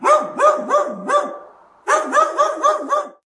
Anjing_Suara.ogg